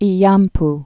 (ē-yämp)